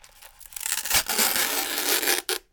大カップ麺フタはがす
open_cup_noodles.mp3